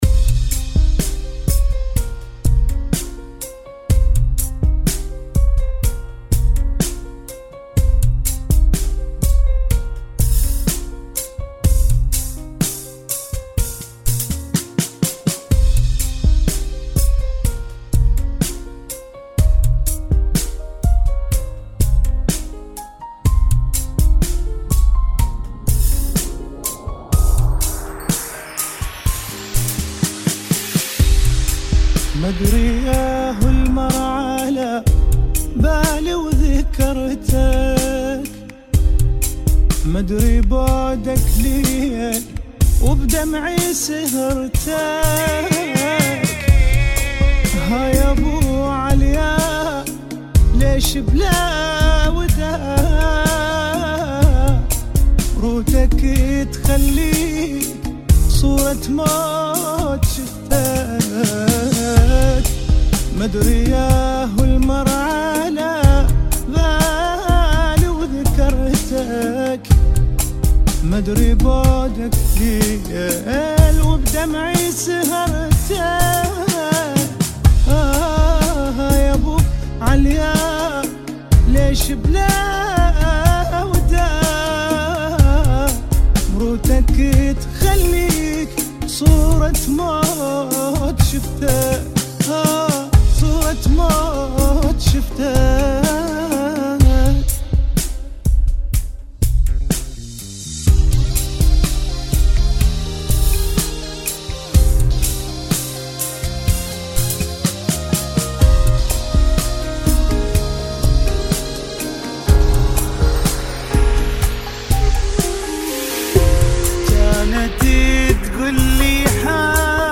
[ 62 Bpm ]
Funky